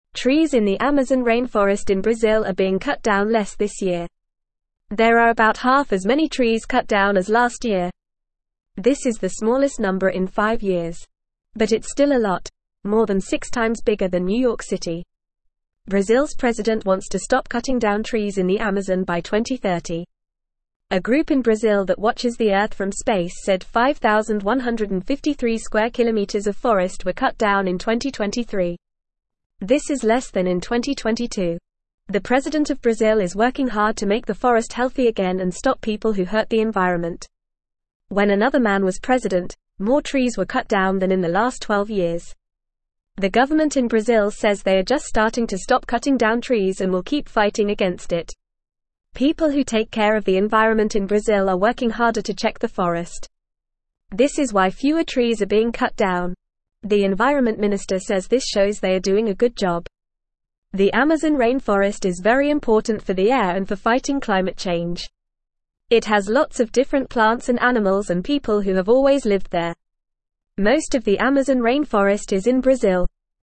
Fast
English-Newsroom-Lower-Intermediate-FAST-Reading-Brazils-Forest-Fewer-Trees-Cut-Down-But-Still-Too-Many.mp3